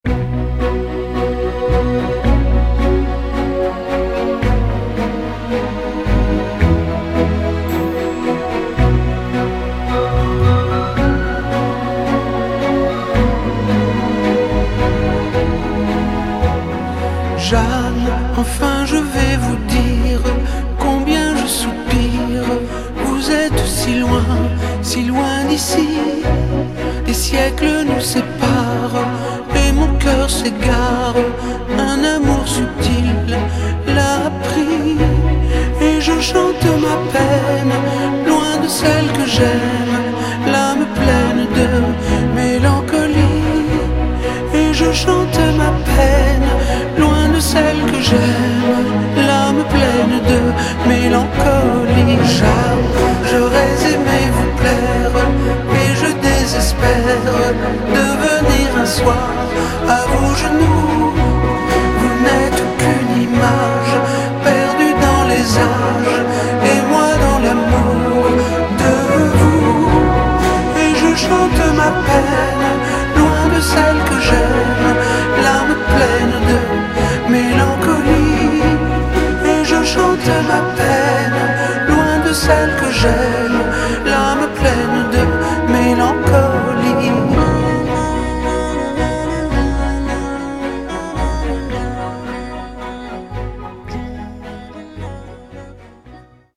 tonalité SI majeur